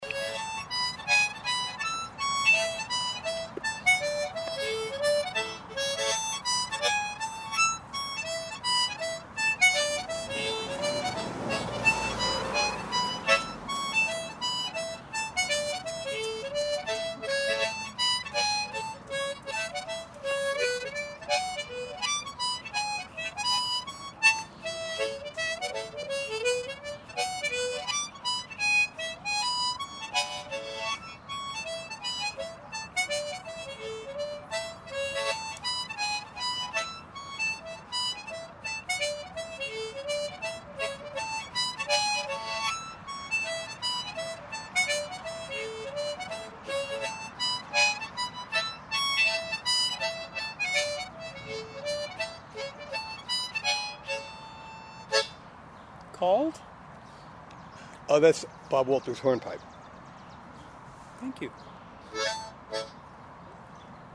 He has also brought his harmonica.